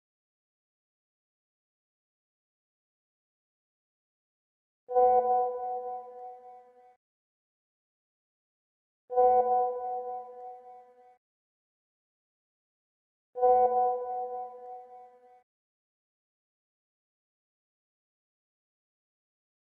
دانلود آهنگ واتساپ 2 از افکت صوتی اشیاء
جلوه های صوتی
برچسب: دانلود آهنگ های افکت صوتی اشیاء دانلود آلبوم صدای پیام واتساپ از افکت صوتی اشیاء